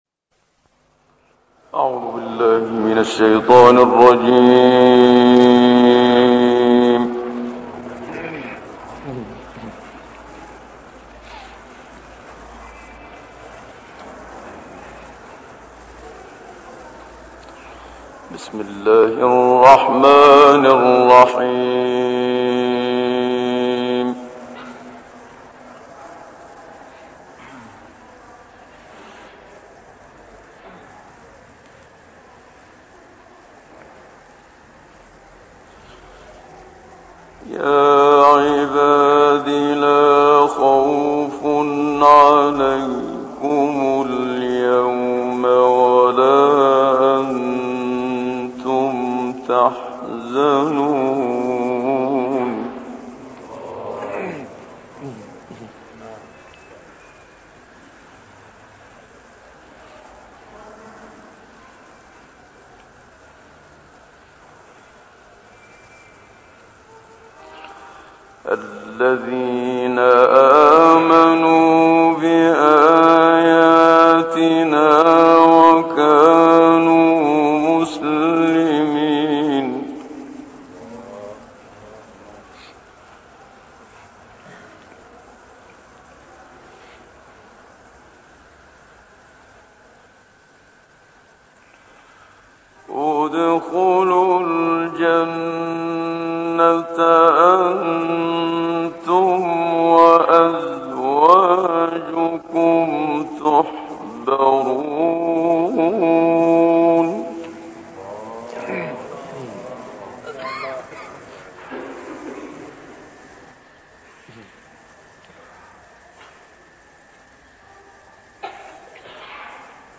تلاوت «منشاوی» در کویت
گروه فعالیت‌های قرآنی: تلاوت آیاتی از سوره زخرف، دخان و قدر با صوت محمد‌صدیق منشاوی ارائه می‌شود.
منشاوی در همین مقام وارد سوره دخان می‌شود و پس از آن قطعات سوره دخان را در مقام عجم تلاوت می‌کند و مطابق معمول و روش کلی تلاوت‌هایی که انجام داده، مجدداً اکتاوخوانی را در دستور کار دارد، در مقام بیات وارد سوره قدر و مجدداً وارد مقام صبا می‌شود و پس از اجرای قطعاتی در این مقام با ورود به مقام بیات فرود می‌آید. از نکات جالب توجه این تلاوت می‌توان به عدم ورود استاد به مقام نهاوند اشاره کرد.